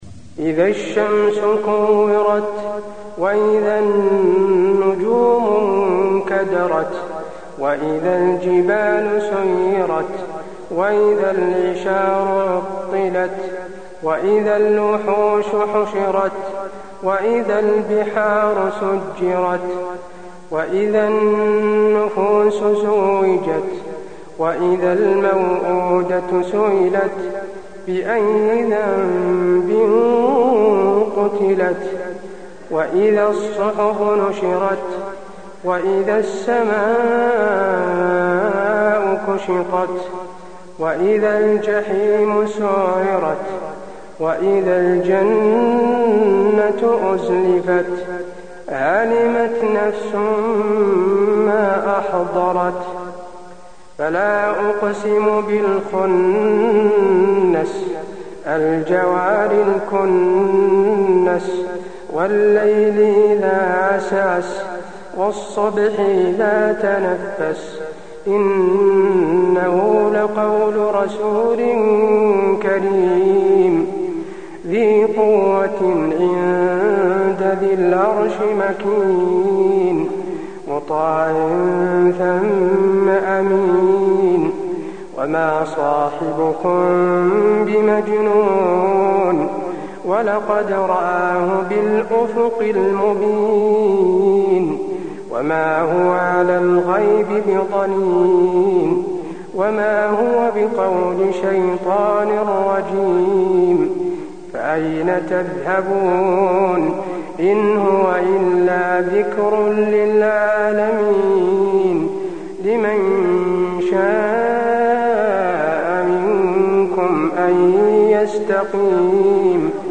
المكان: المسجد النبوي التكوير